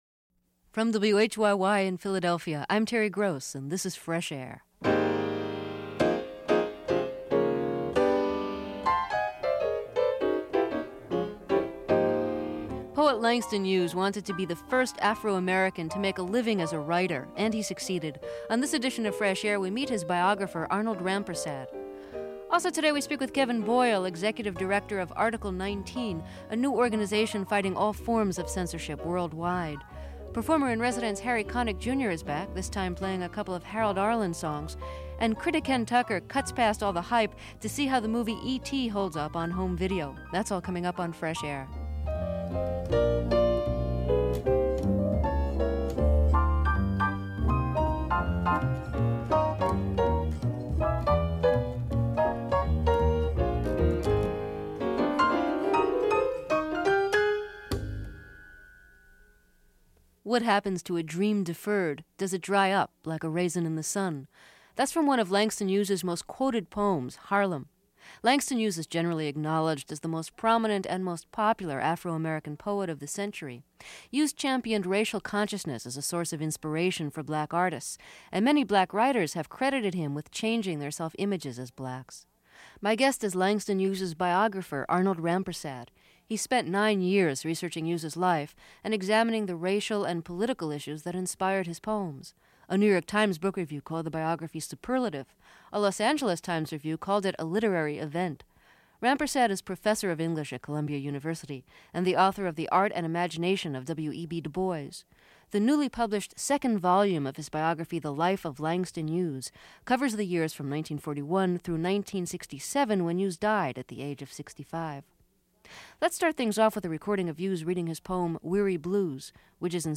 Interview Larry Flynt